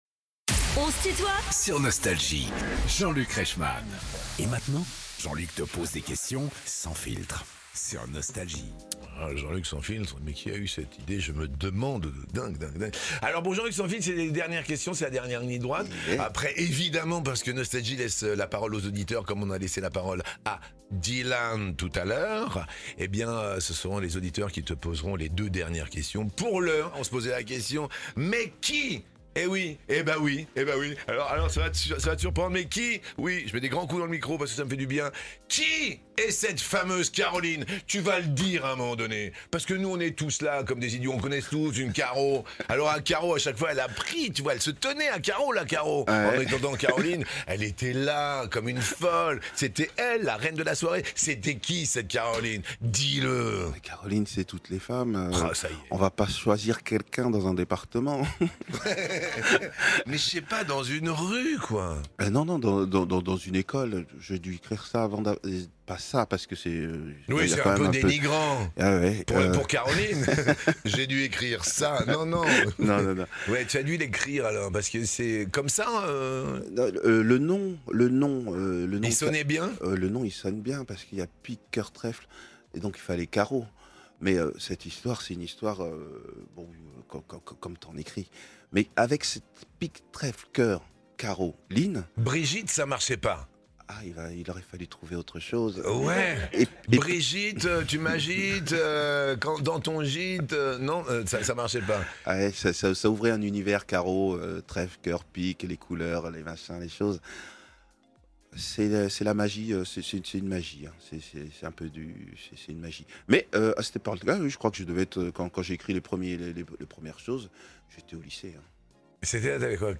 Les plus grands artistes sont en interview sur Nostalgie.